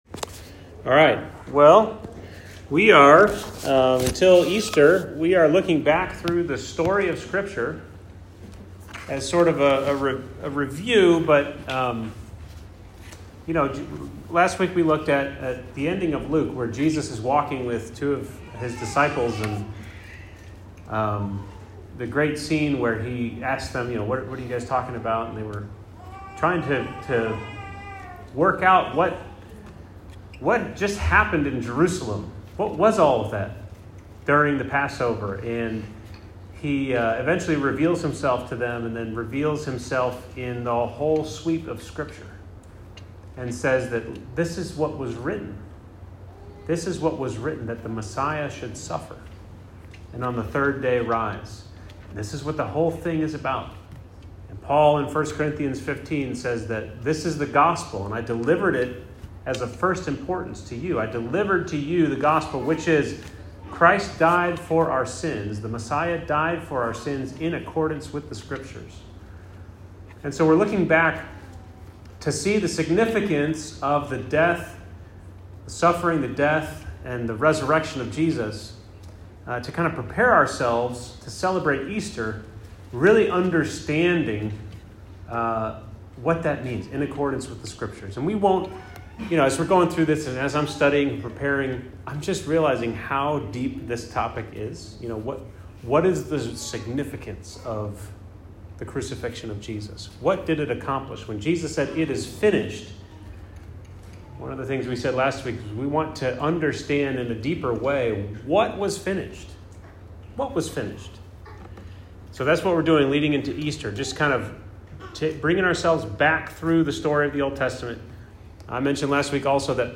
Weekly sermon audio from Emmanuel Christian Fellowship in Lexington, KY.